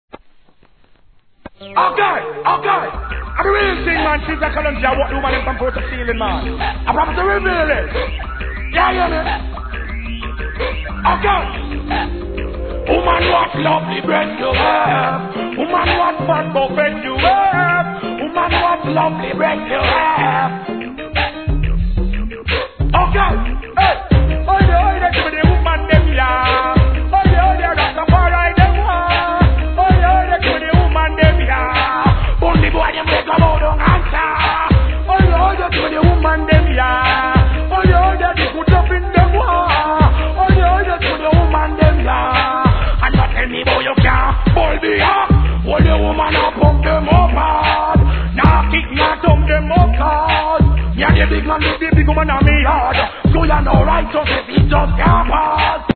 REGGAE
ハーコーJUGGLIN'!!